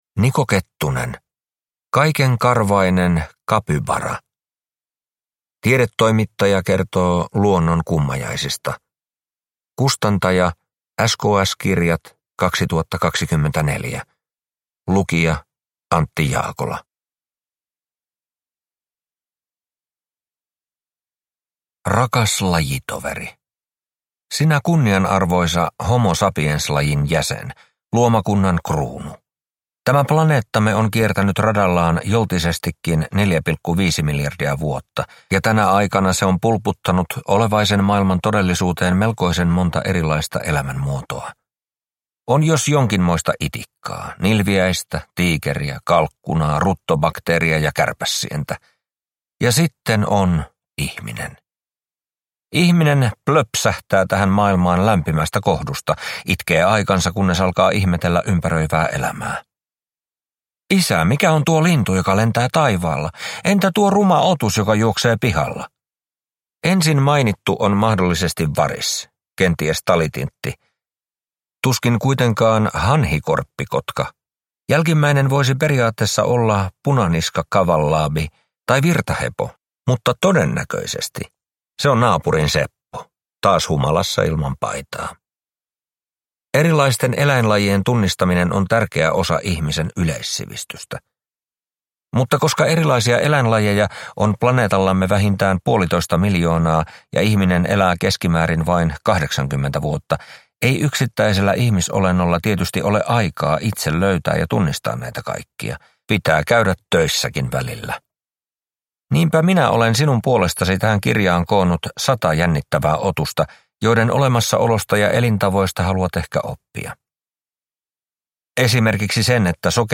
Kaikenkarvainen kapybara – Ljudbok